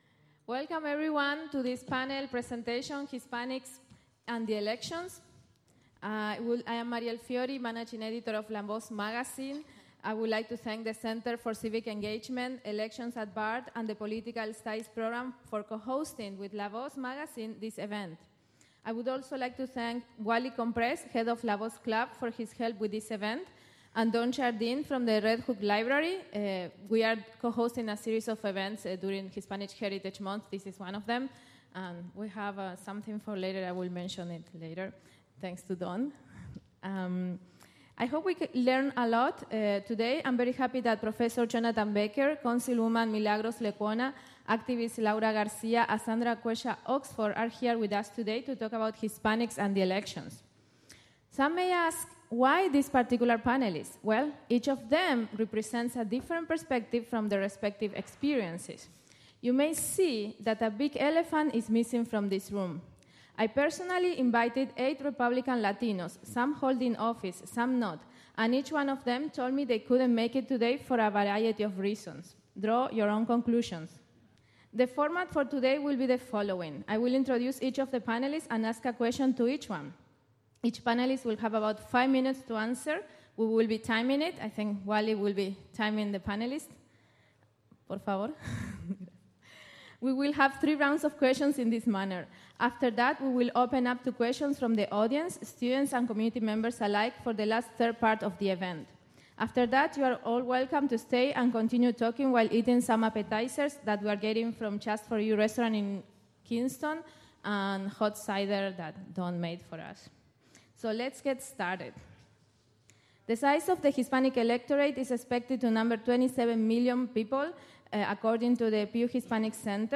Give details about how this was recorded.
In this special broadcast, an excerpt from a panel presentation on Hispanics and the Elections held at Bard College October 5, 2016.